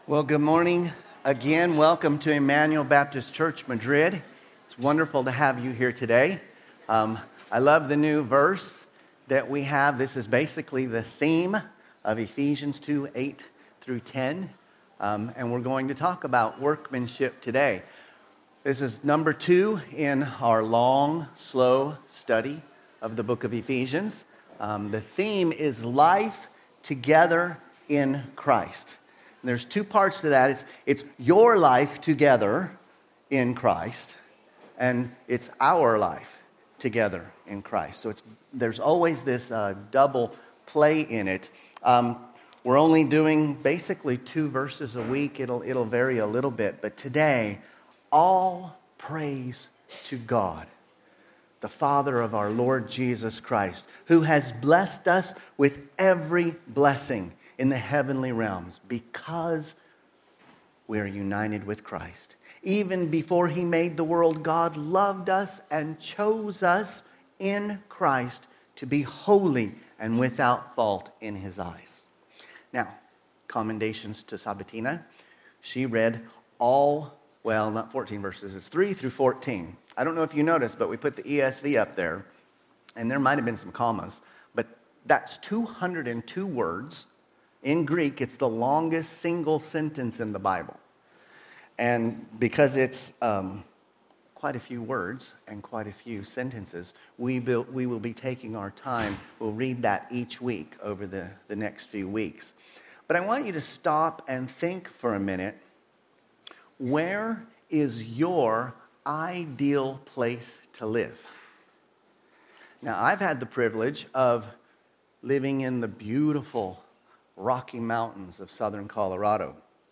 Sermons – Immanuel Baptist Church | Madrid